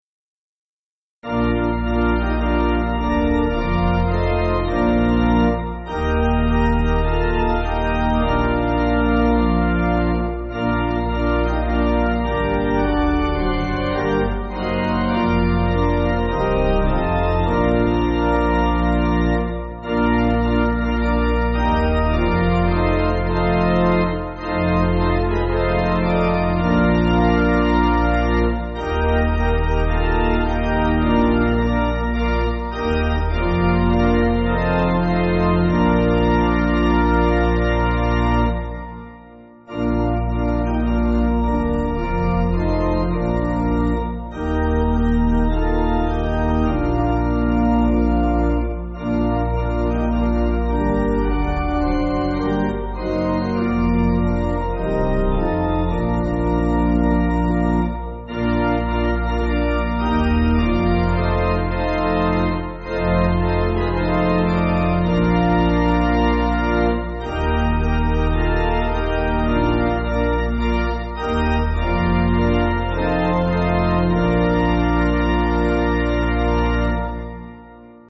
Organ
(CM)   3/Bb